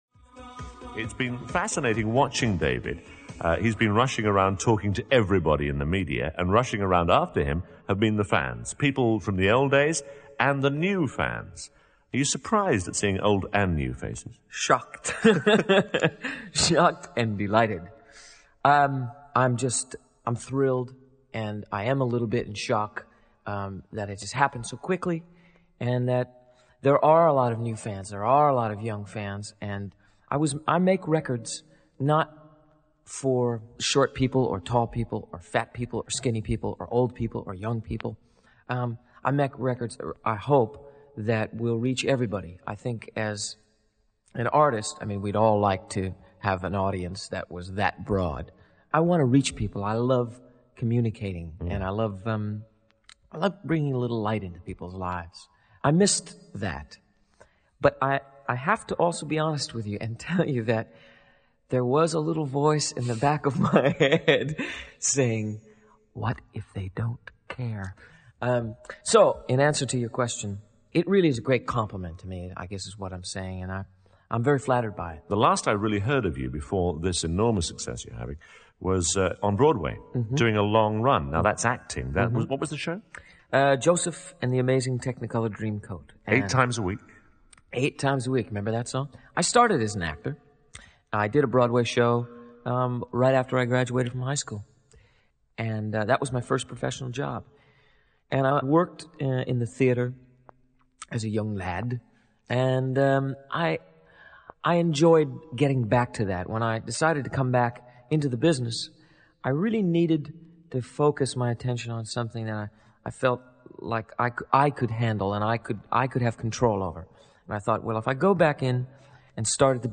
1985 Radio interview on BBC1